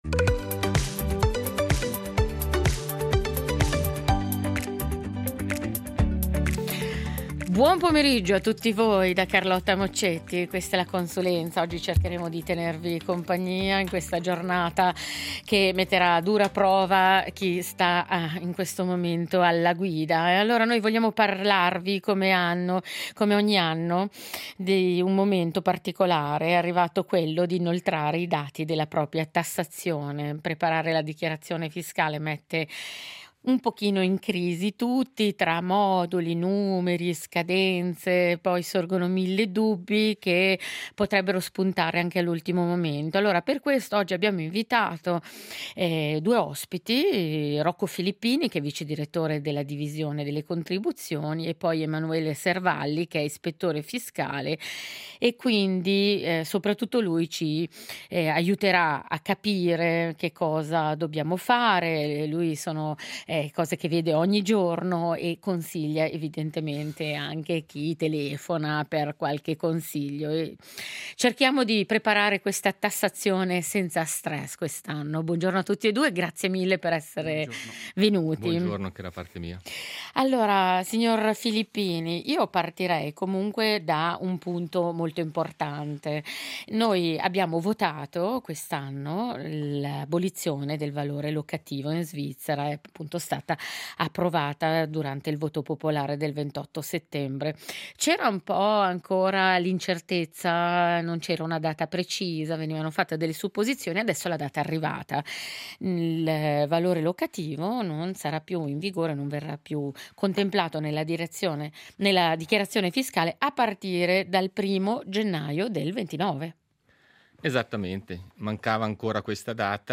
Preparare la dichiarazione fiscale mette in crisi un po’ tutti: moduli, numeri, scadenze… e mille dubbi che spuntano all’ultimo momento. Per questo oggi abbiamo invitato un tassatore che queste cose le vede ogni giorno e che ci aiuterà a capire come muoverci senza stress. Gli faremo le domande più comuni e ci faremo spiegare, in parole semplici, come affrontare le tasse con un po’ più di serenità.